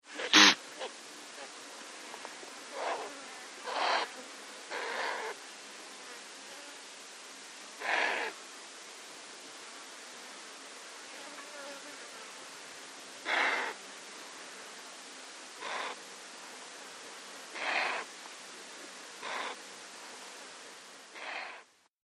Записи сделаны в дикой природе и передают атмосферу саванны.
Джимела грациозная антилопа